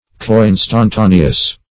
Search Result for " coinstantaneous" : The Collaborative International Dictionary of English v.0.48: Coinstantaneous \Co*in"stan*ta"ne*ous\, a. Happening at the same instant.
coinstantaneous.mp3